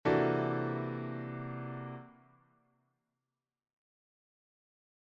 Il s’agit de voicings pour accords de Dominante comportant des altérations, composés d’une triade à la main droite, sur un triton à la main gauche.